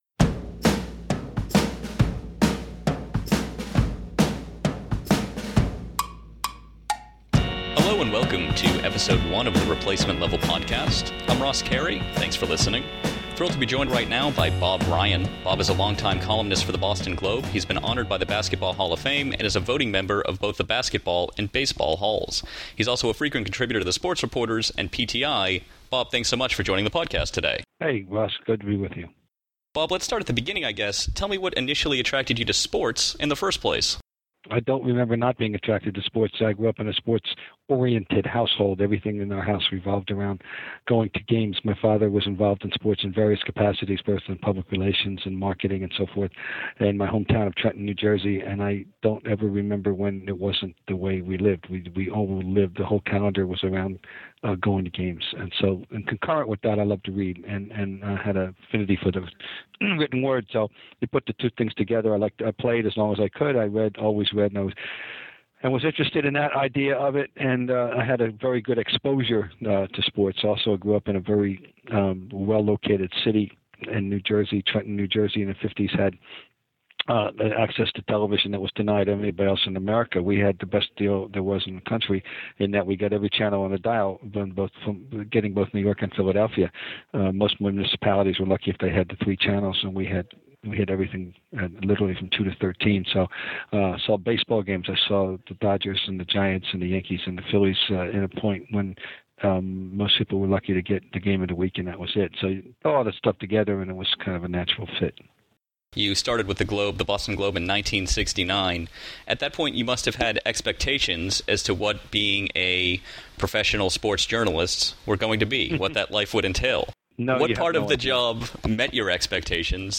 Bob doesn't plan to vote for Barry Bonds or Roger Clemens in 2013, and he explains why in this interview. Bob also talked about how journalism has changed since his first year at the Globe, why he loves sports, and why he is happy to be called a baseball purist.